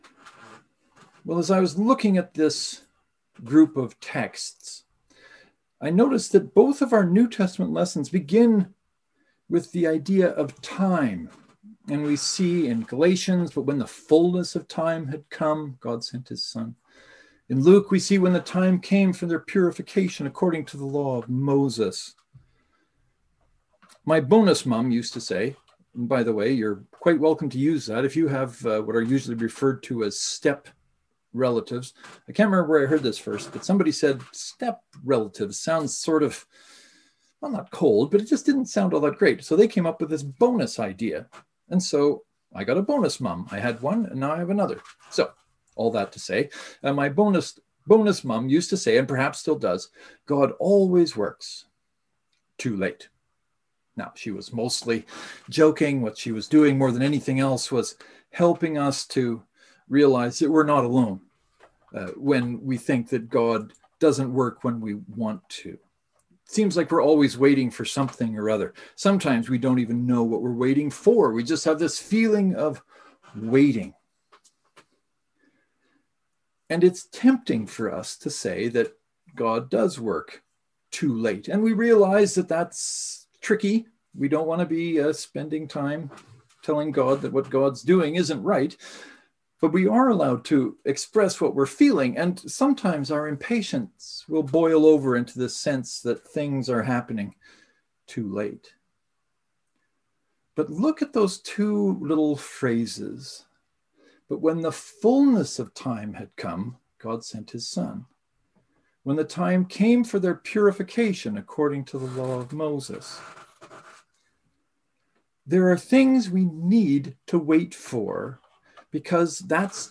“We are in the family” Knox and St. Mark’s Presbyterian joint service (to download, right click and select “Save Link As .